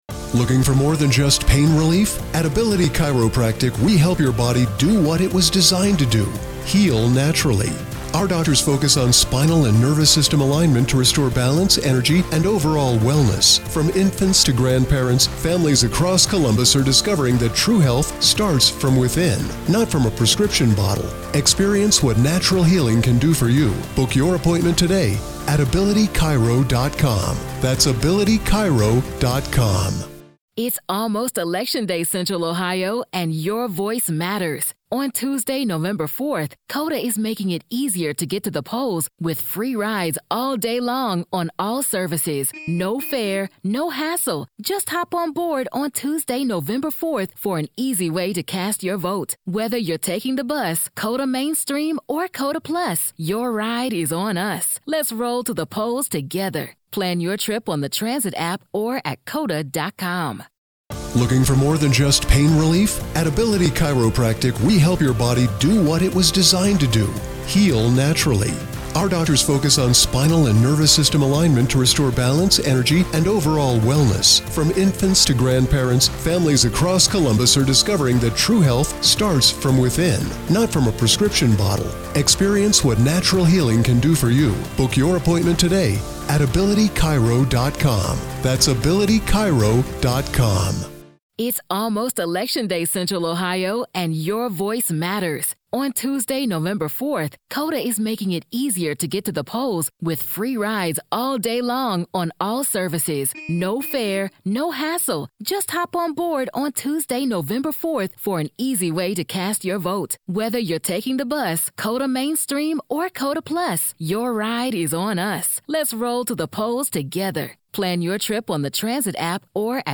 The conversation also looks at how juries perceive this kind of toxic family loyalty: is it recognizable to outsiders, or does it take expert framing to expose just how abnormal it is?